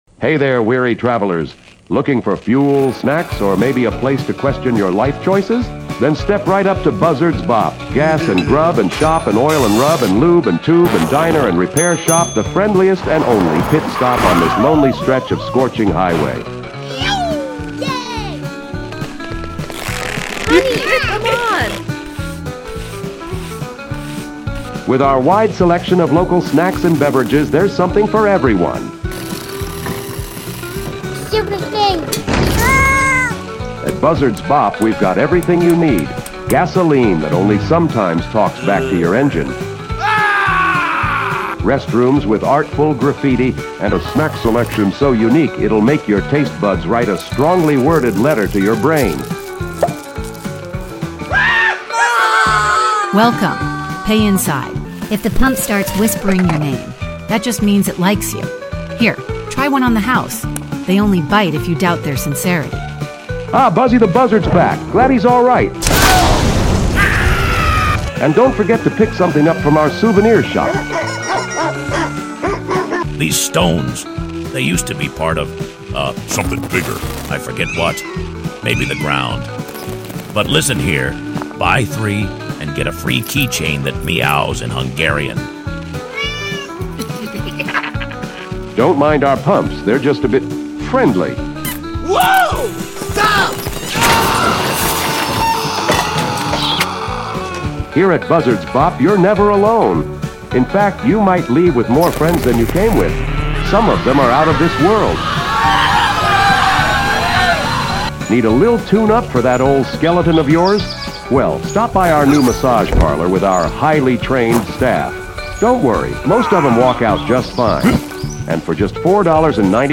From hilariously unsettling AI-generated voices to high-quality cinematic effects, this film takes advantage of the latest production tools from ElevenLabs and Production Crate to bring the madness to life.